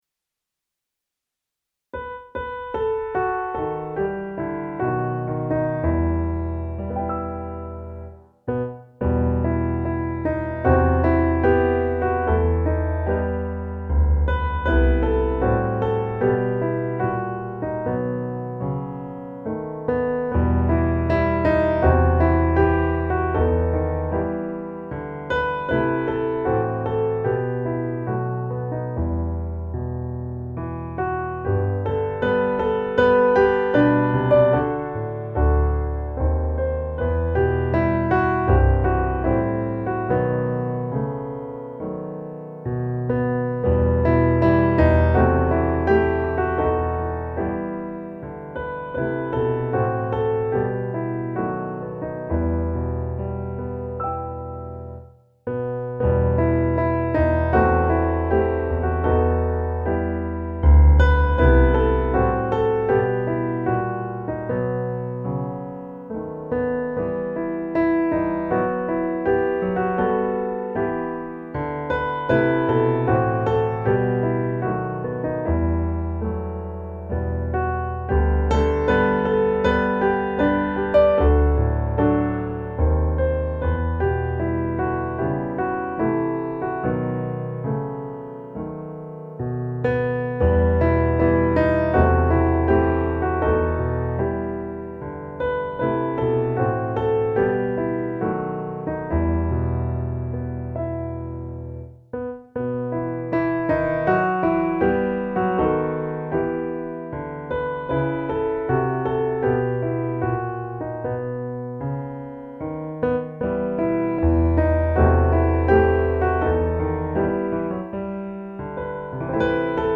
musikbakgrund
Musikbakgrund Psalm